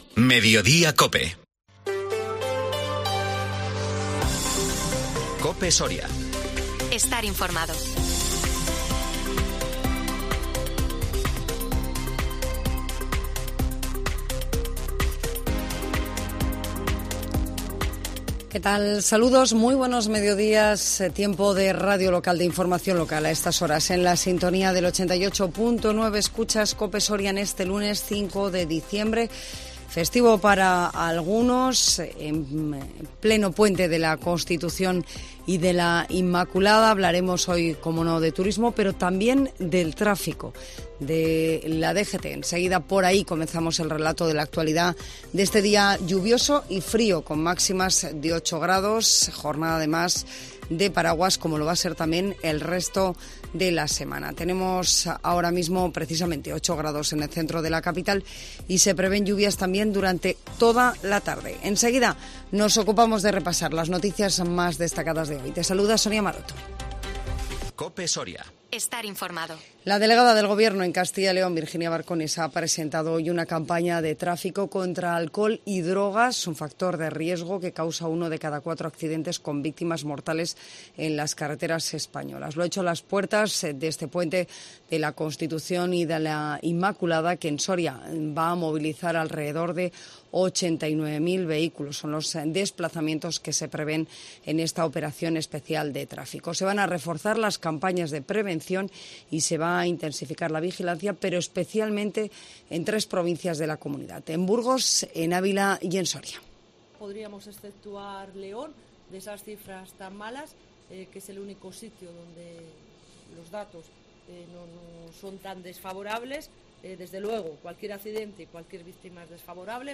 INFORMATIVO MEDIODÍA COPE SORIA 5 DICIEMBRE 2022